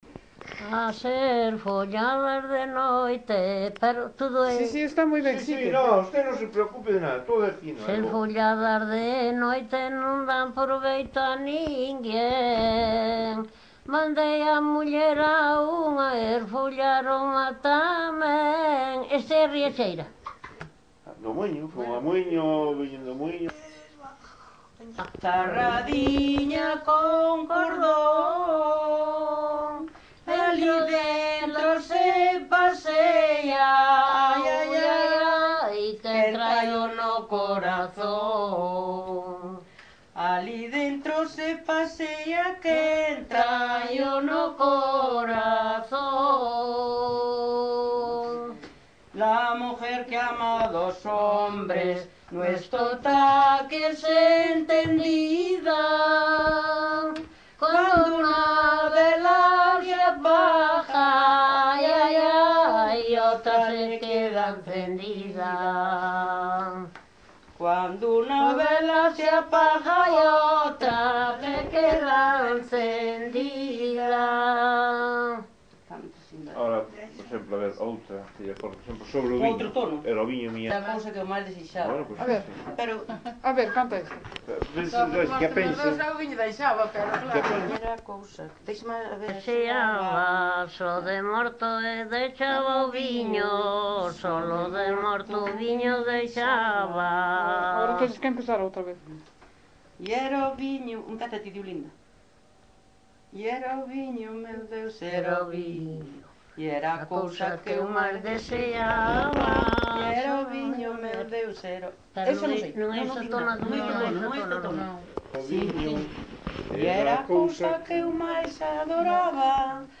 Tipo de rexistro: Musical
Soporte orixinal: Casete
Datos musicais Refrán
Instrumentación: Voz
Instrumentos: Voces femininas